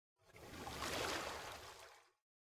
artifact_water.ogg